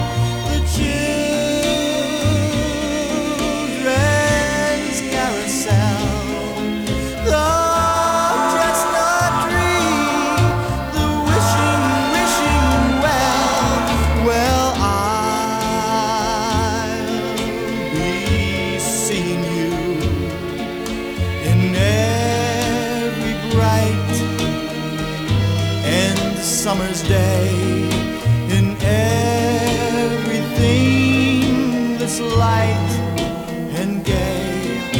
# Doo Wop